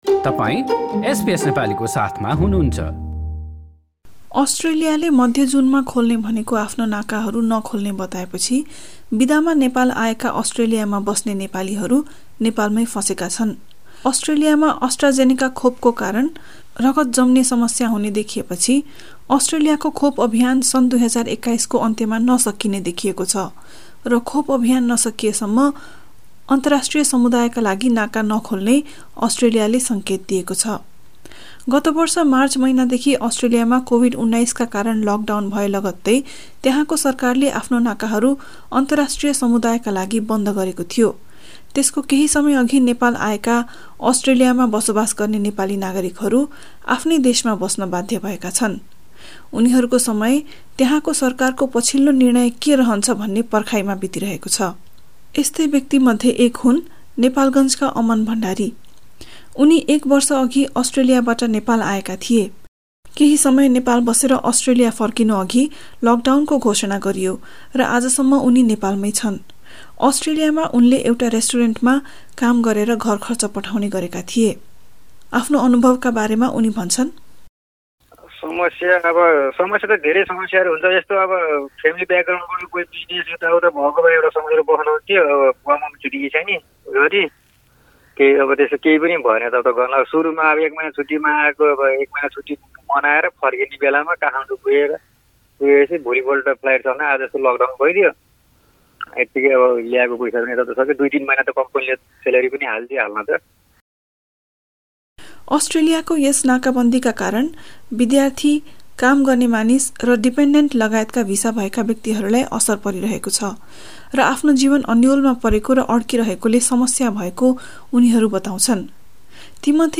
त्यस्तै अवस्थामा रहेका व्यक्तिहरुसँगको कुराकानी सहित
एक रिपोर्ट।